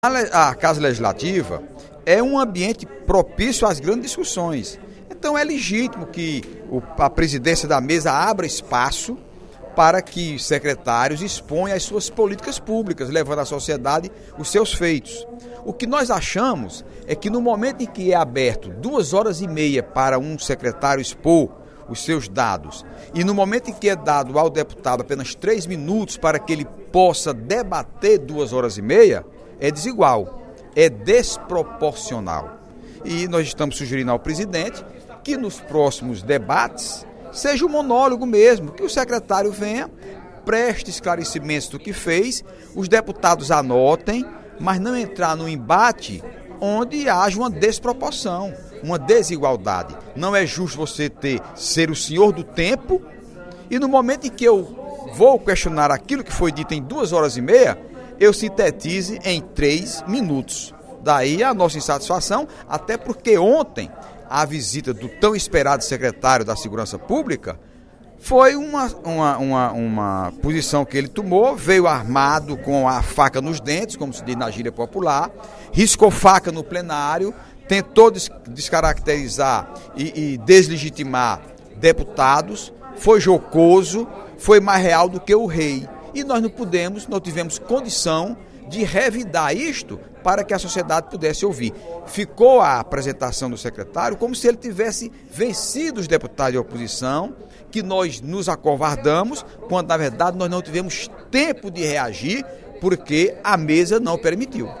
O deputado Heitor Férrer (PDT) criticou, durante o primeiro expediente da sessão plenária desta quinta-feira (08/08), a postura do secretário de Segurança Pública e Defesa Social, coronel Francisco Bezerra, que esteve ontem na Assembleia Legislativa para prestar informações sobre as ações de sua pasta.
Em aparte, vários deputados endossaram as queixas contra o secretário.